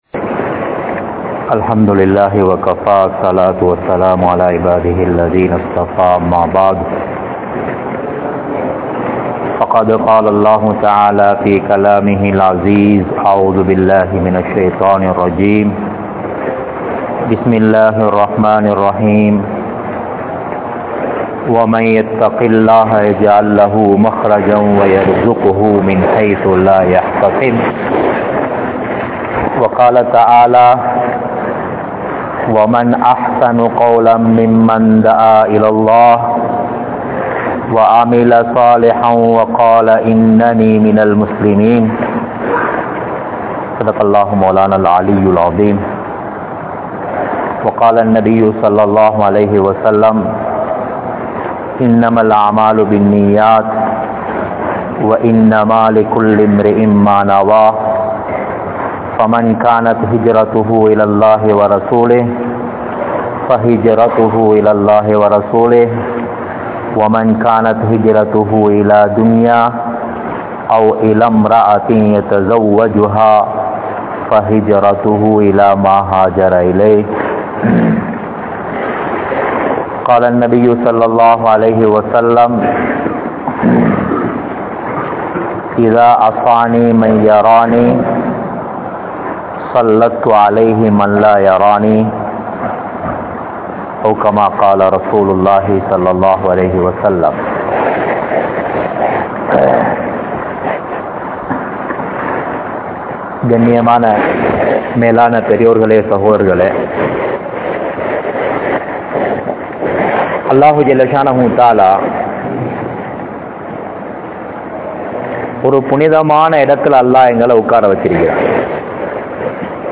Allah`vin Uthavi Veanduma? (அல்லாஹ்வின் உதவி வேண்டுமா?) Jumua Night Bayan | Audio Bayans | All Ceylon Muslim Youth Community | Addalaichenai
Grand Jumua Masjidh(Markaz)